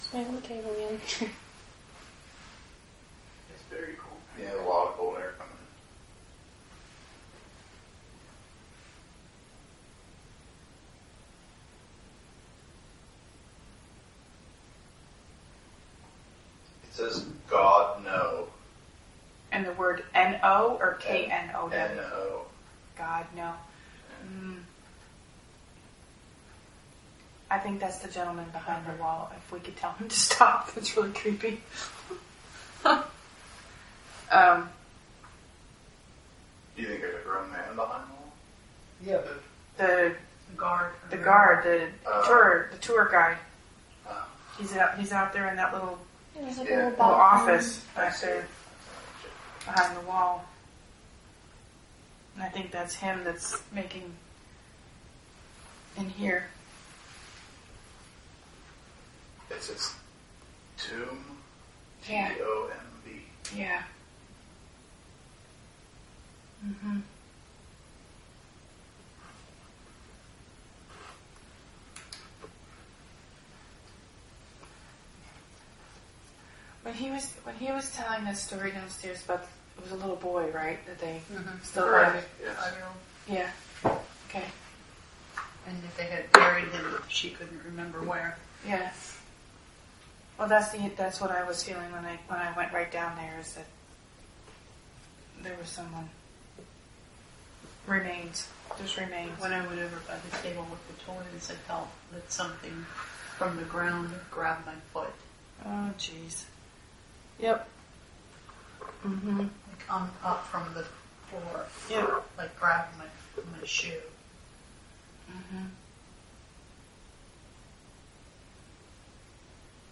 Recorder 4 – Spirit Box:
• Another knock and possibly footsteps could be heard at 3:01.